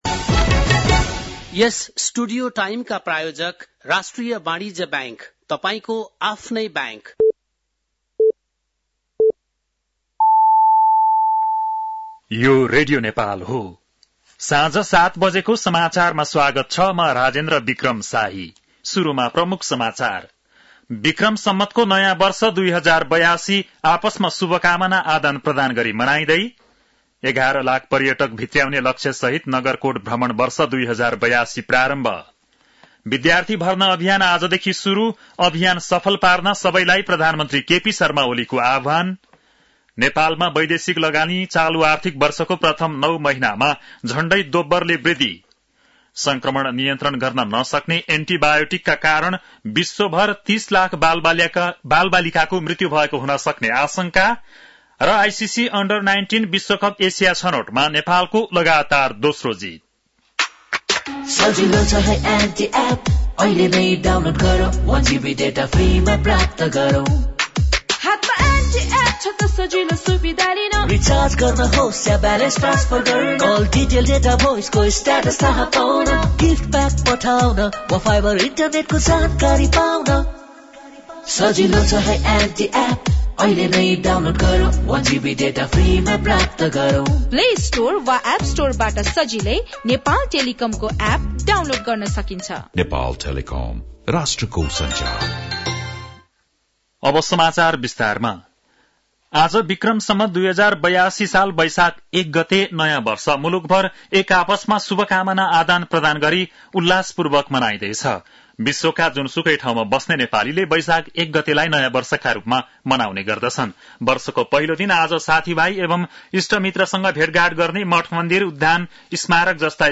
बेलुकी ७ बजेको नेपाली समाचार : १ वैशाख , २०८२
7.-PM-nepali-News-1-1.mp3